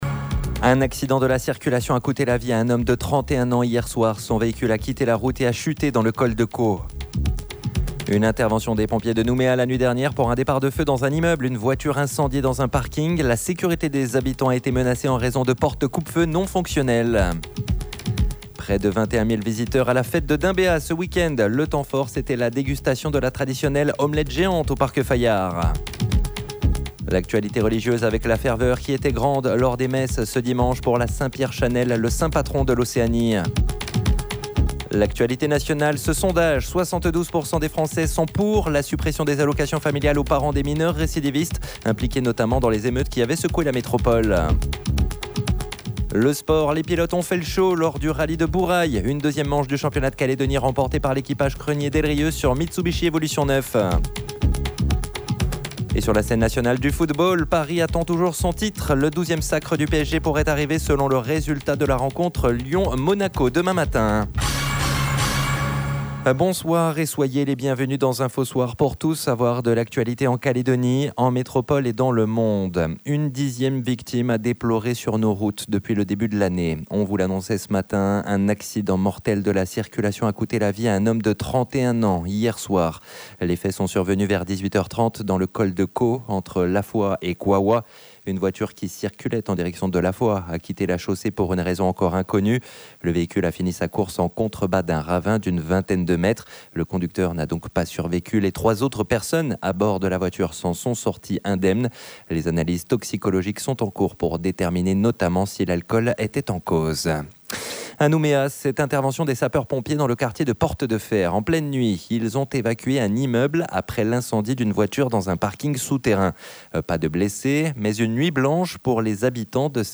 JOURNAL : INFO WEKEEND DIMANCHE SOIR